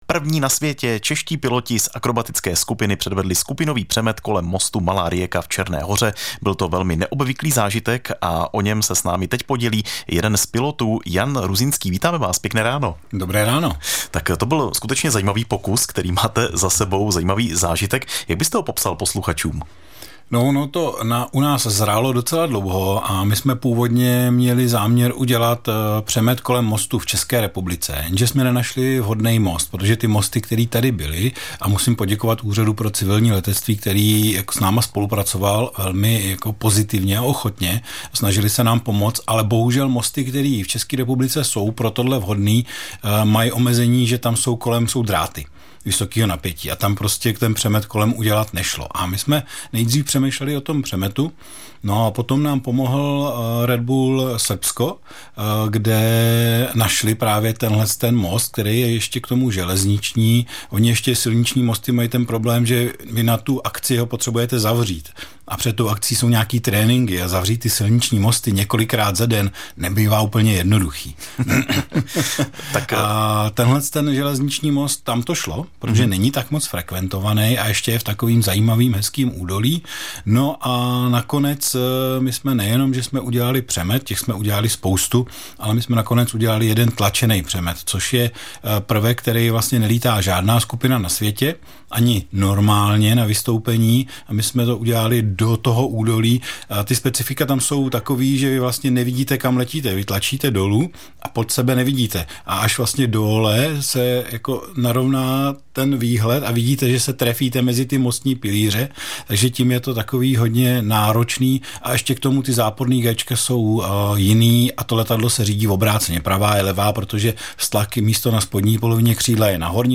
Zprávy pro Pardubický kraj: Pomoc pro lidi bez domova pod jednou střechou.